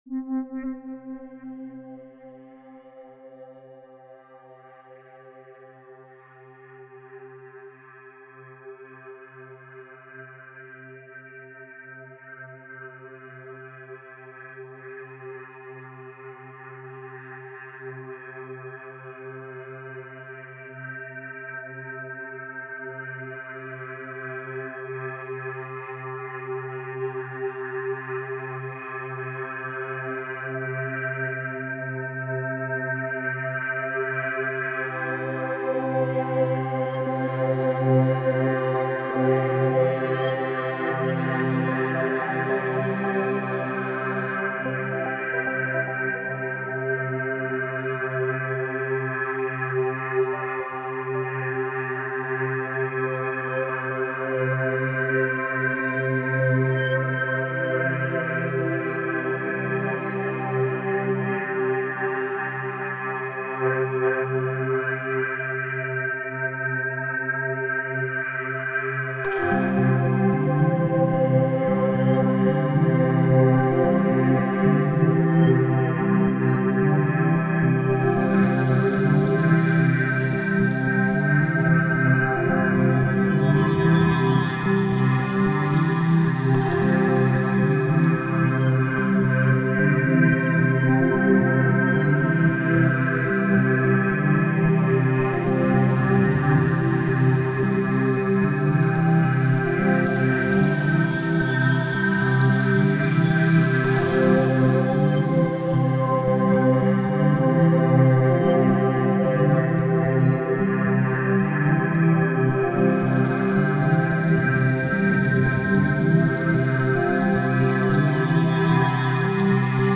Пример саундтрека. Космическая атмосфера, позитивное настроение, подойдет для главного меню игры